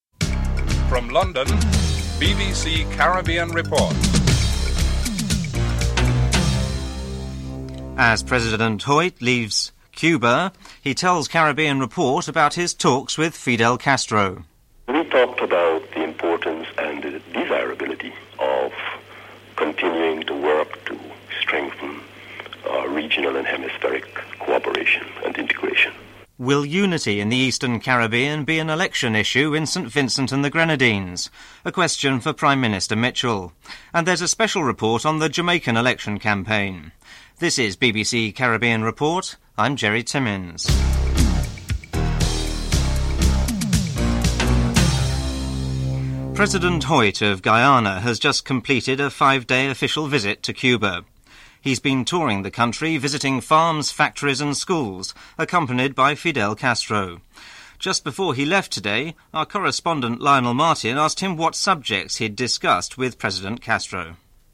2. Interview with President Desmond Hoyte of Guyana on his five day official visit to Cuba (00:46-03:32)
4. Interview with James Mitchell, Prime Minister of St. Vincent and the Grenadines on the unity issue in the Eastern Caribbean in the up-coming elections (05:02-07:45)